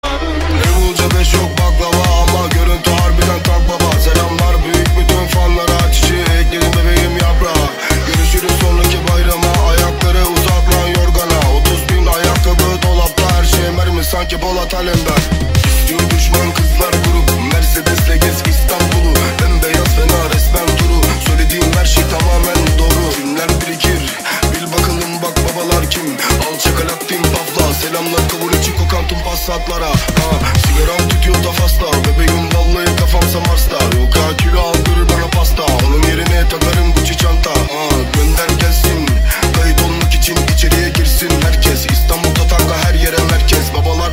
Kategori Elektronik